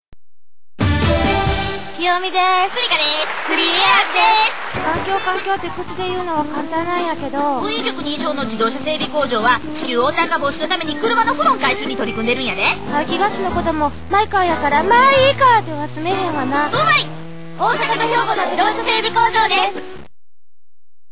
女性漫才編
ＳＥ(コンビのテーマっぽい曲で登場、拍手)